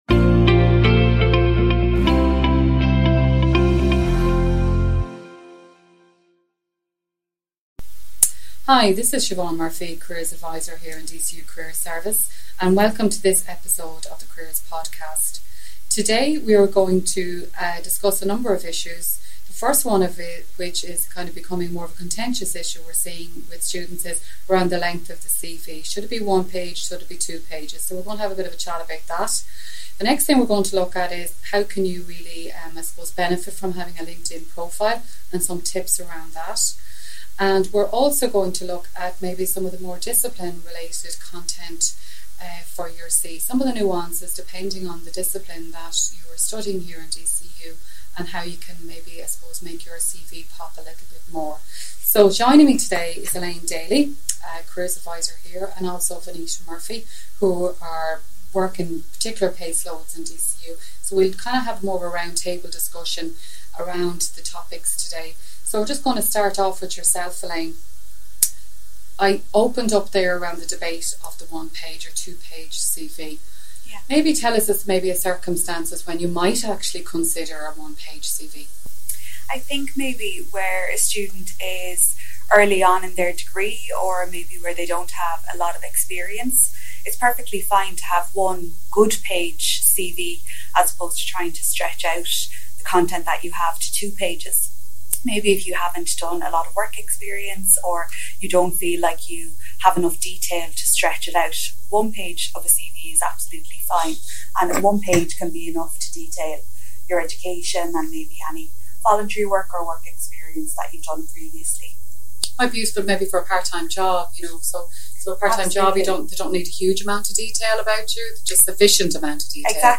This episode features a roundtable discussion